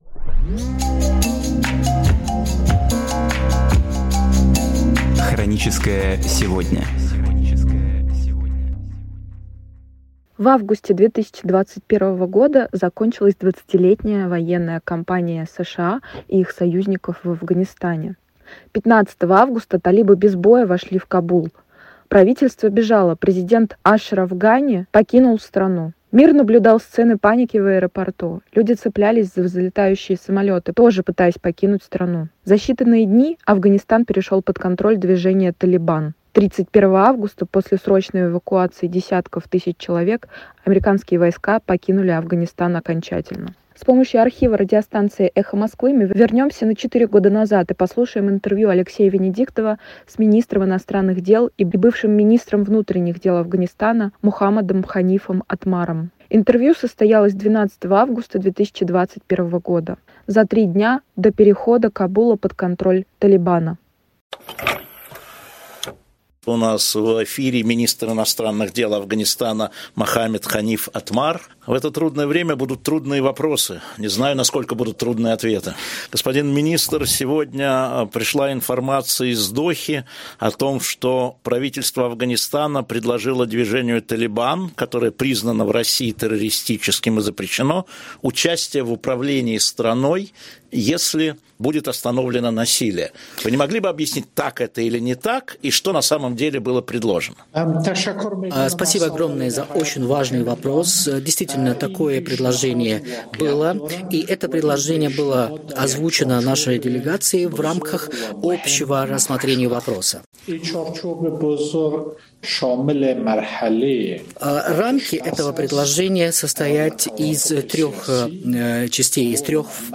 Программы из архива «Эха Москвы»